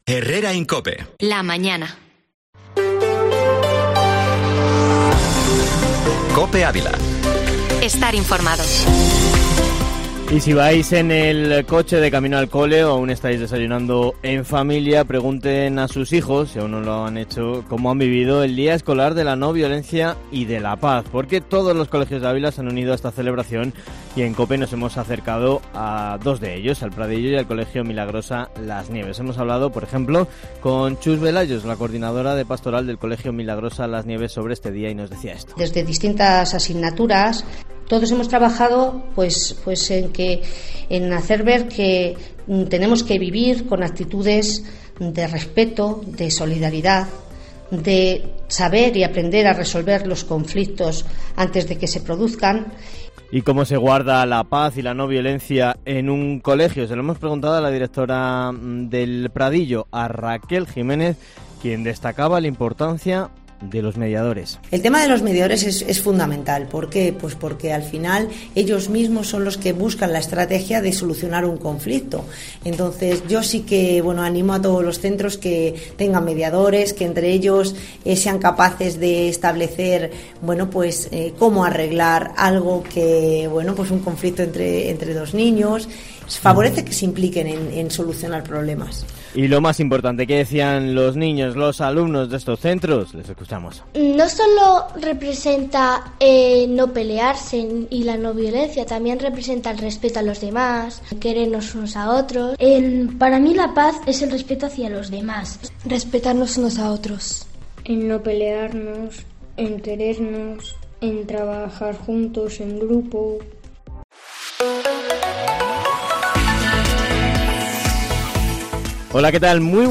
Informativo Matinal Herrera en COPE Ávila, información local y provincial con